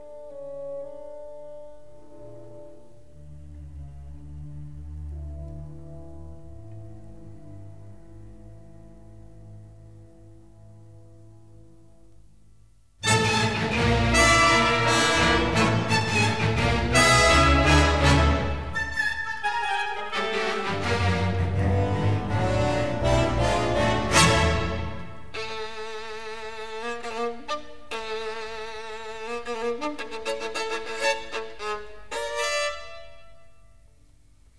in D Op. 36
Recorded in the Kingsway Hall.
London in December 1956